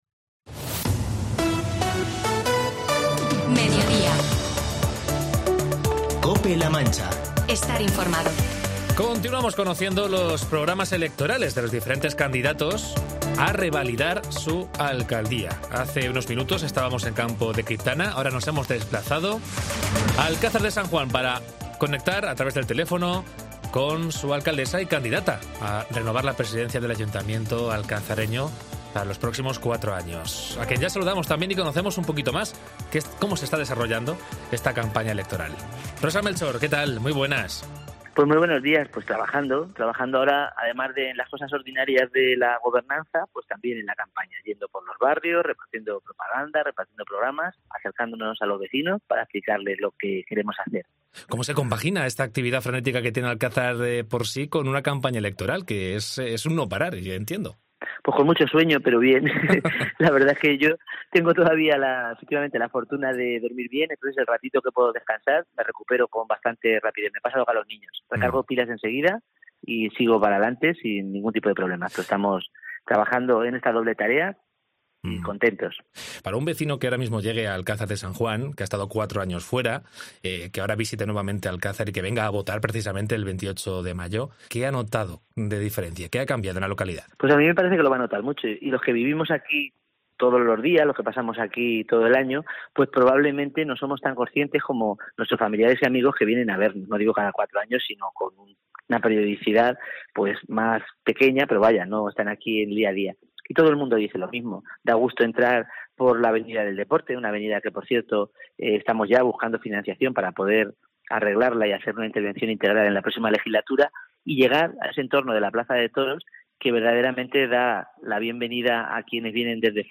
Entrevista a Rosa Melchor, candidata a renovar la alcaldía del Ayuntamiento de Alcázar de San Juan